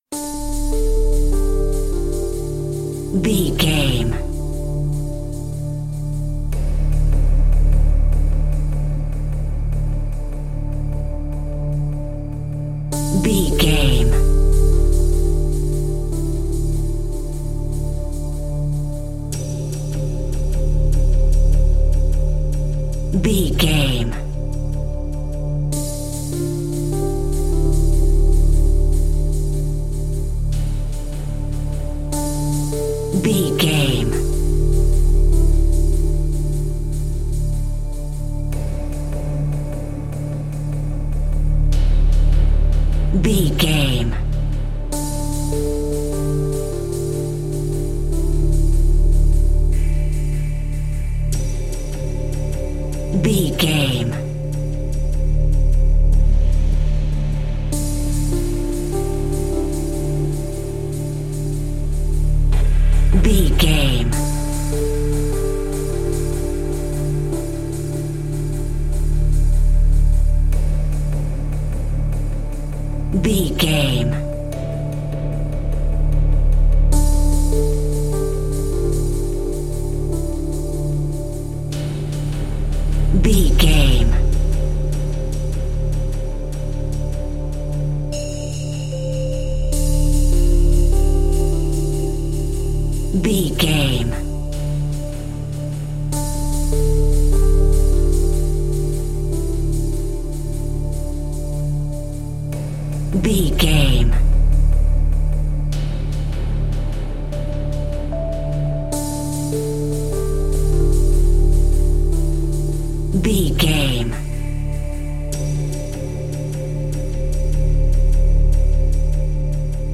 Thriller
Aeolian/Minor
synthesiser
electric piano
percussion